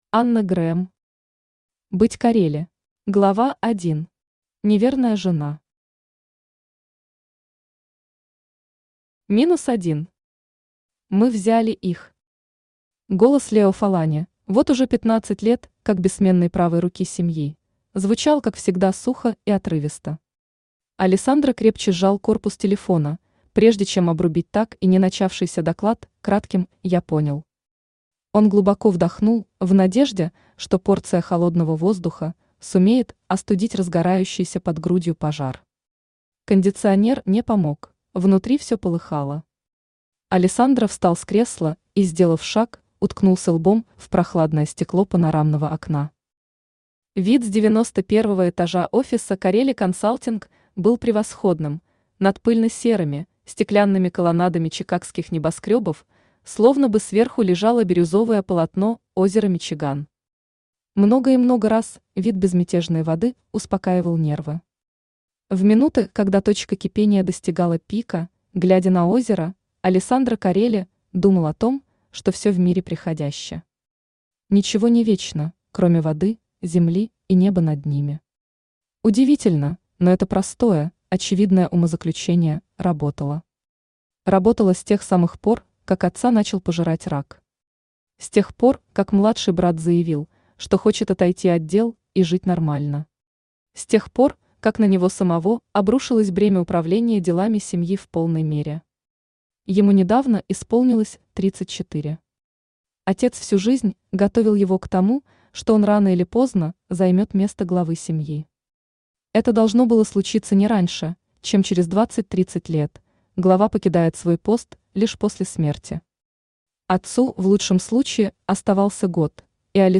Аудиокнига Быть Корелли | Библиотека аудиокниг
Aудиокнига Быть Корелли Автор Анна Грэм Читает аудиокнигу Авточтец ЛитРес.